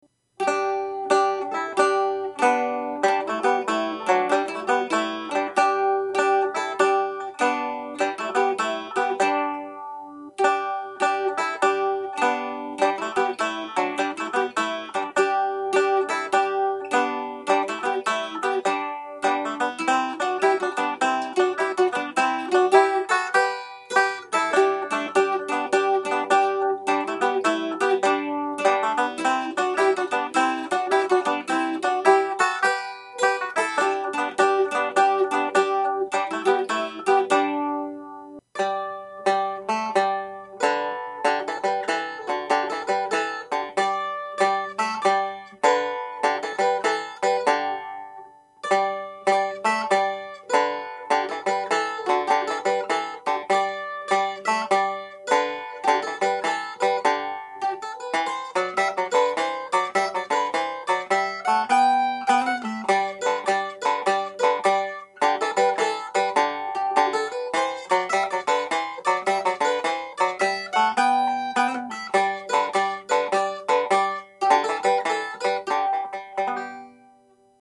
Banjo and Mandolin playing together